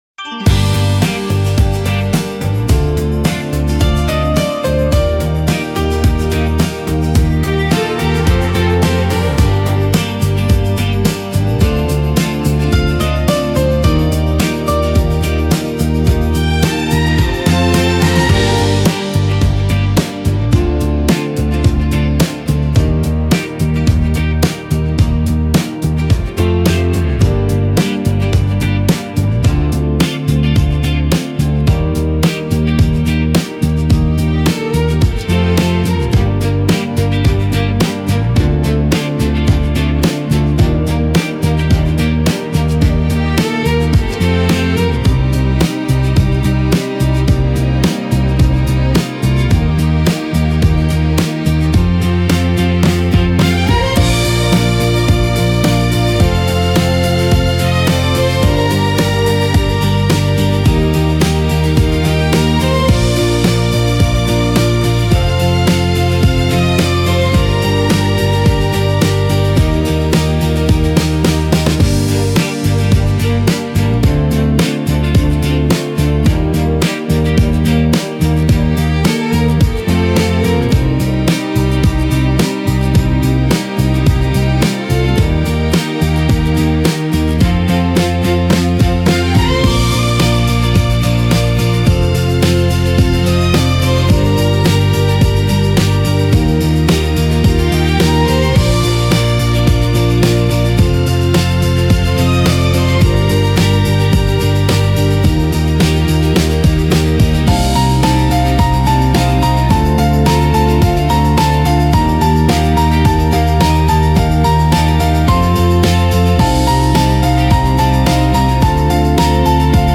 Жанр: Pop Music